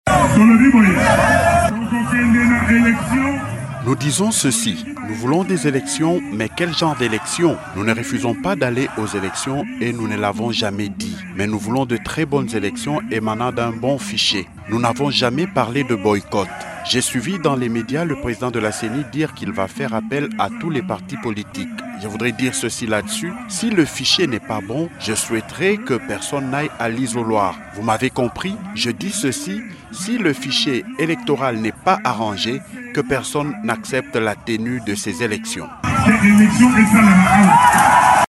Il l’a dit au cours du meeting organisé conjointement avec Moise Katumbi, Augustin Matata Ponyo et Delly Sessanga sur la place Sainte Thérèse dans la commune de Ndjili.